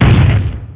mega_bouncehard2.wav